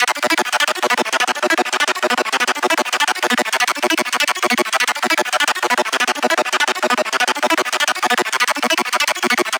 Arp:
Chinges-Arp-B-200.wav